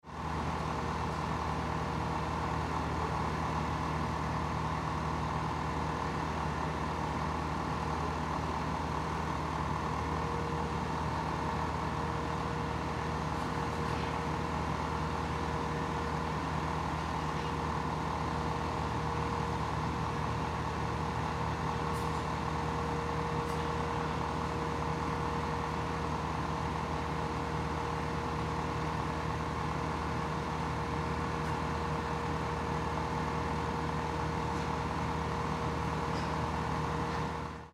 Звук автомобильного крана на холостых оборотах